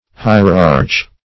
Hierarch \Hi"er*arch\ (h[imac]"[~e]r*[aum]rk`), n. [LL.